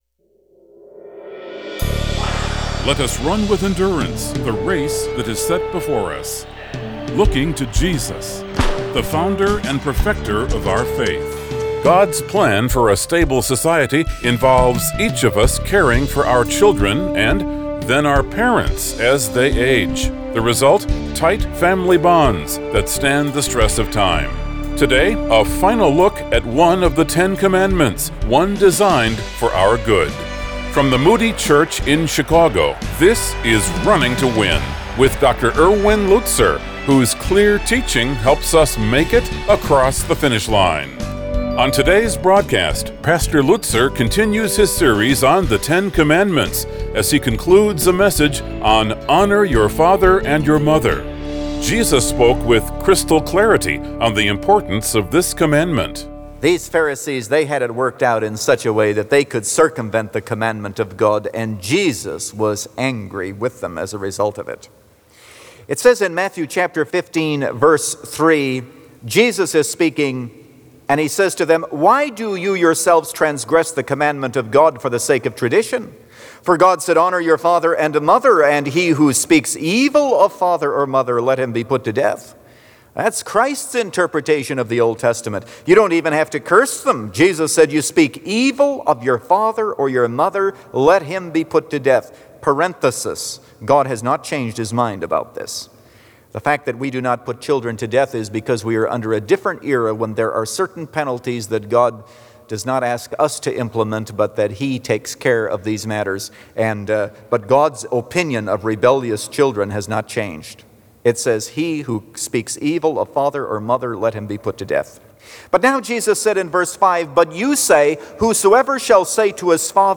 Since 1998, this 15-minute program has provided a Godward focus.